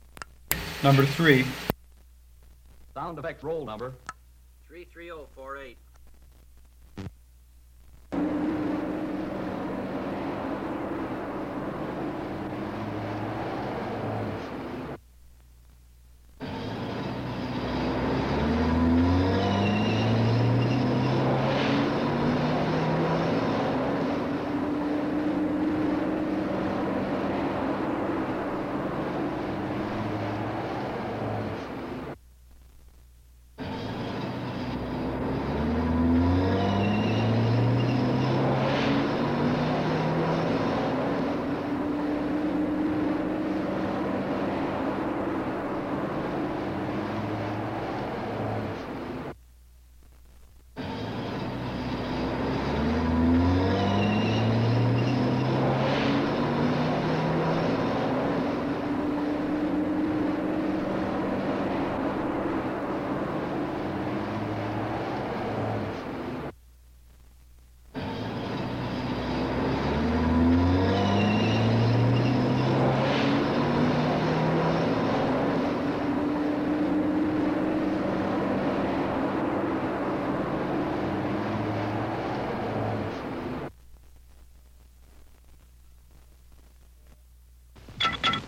老式卡车 " G1403卡车加速器
描述：加速，吱吱，发动机隆隆声。几次重复。非常大的卡车。 这些是20世纪30年代和20世纪30年代原始硝酸盐光学好莱坞声音效果的高质量副本。 40年代，在20世纪70年代早期转移到全轨磁带。我已将它们数字化以便保存，但它们尚未恢复并且有一些噪音。
Tag: 卡车 交通运输 光学 经典